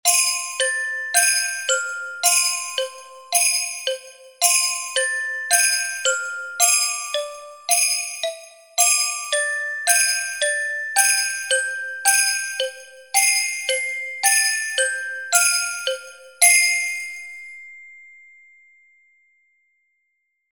Jingle_Bells_Lento_55.mp3